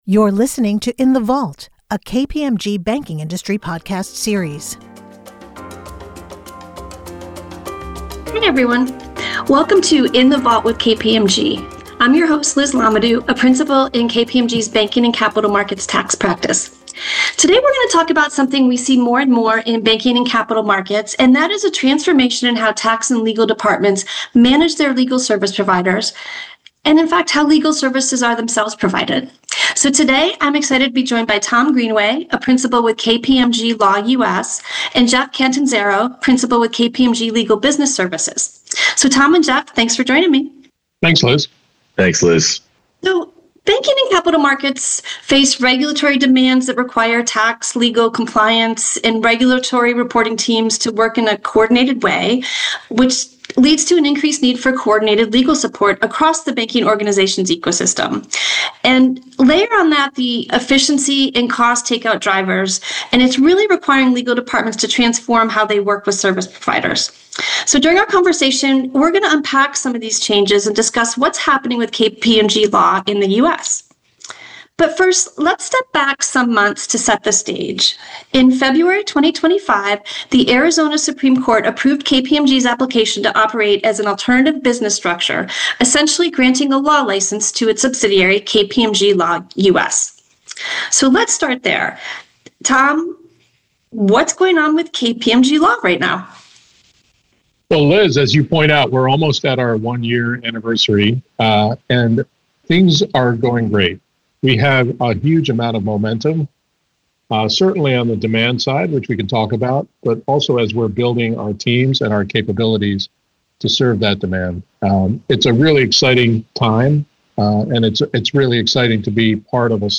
Whether you are looking to streamline entity governance or manage documentation expectations, this conversation provides a roadmap for modern tax leadership.